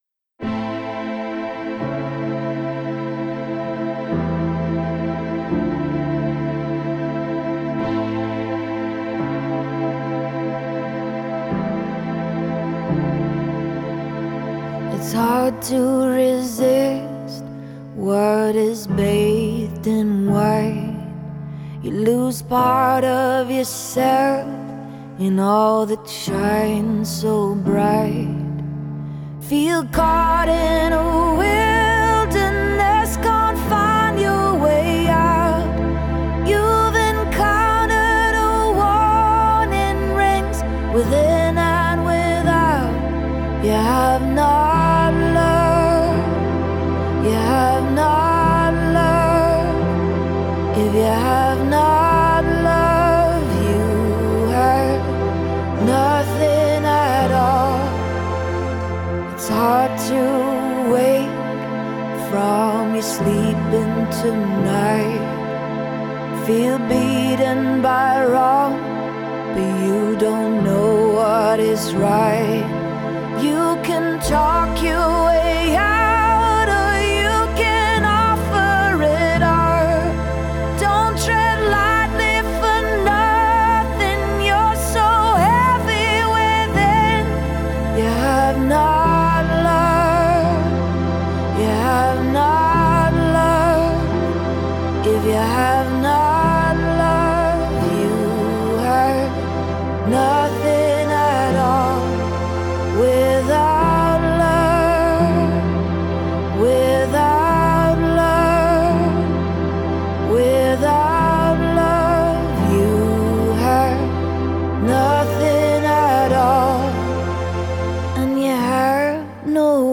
Жанр: pop · female vocalists · indie · singer-songwriter